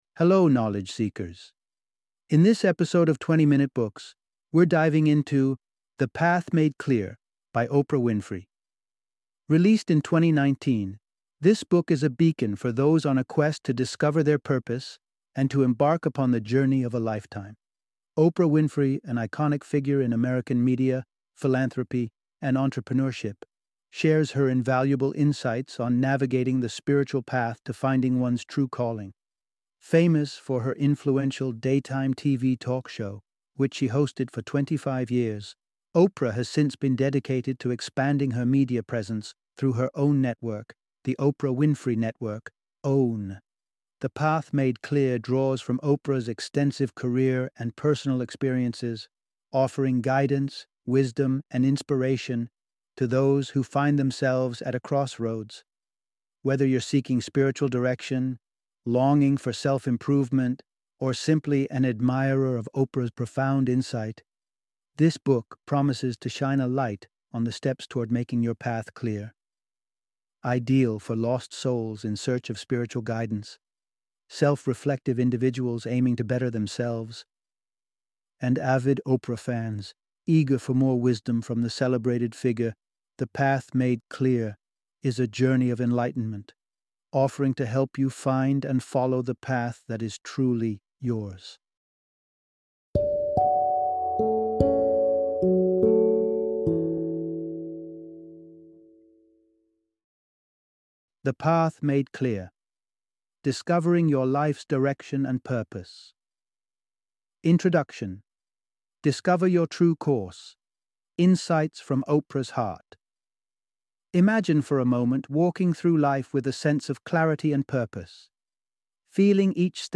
The Path Made Clear - Audiobook Summary
The Path Made Clear - Book Summary